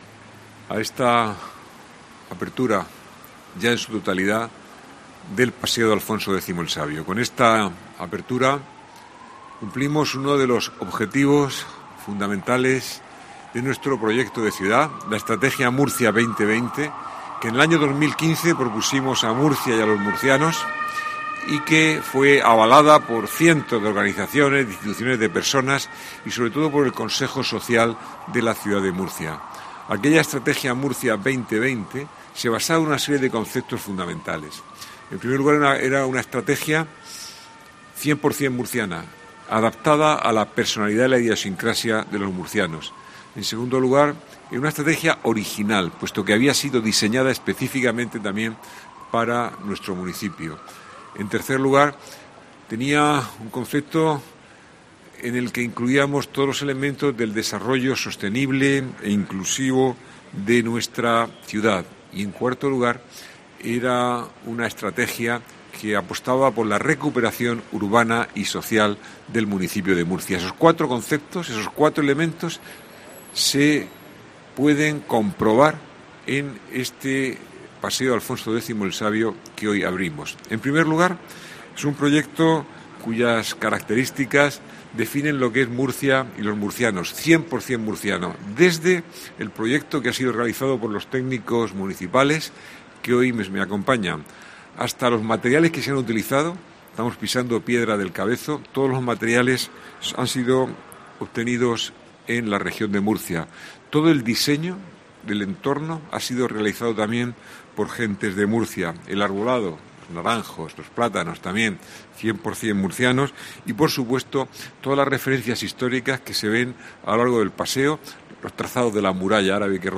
José Ballesta, alcalde de Murcia, presenta el final de las obras de Alfonso X